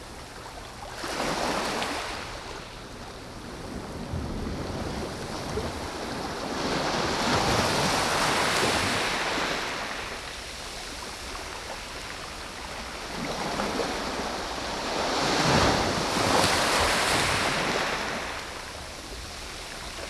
sea_shore_mono.wav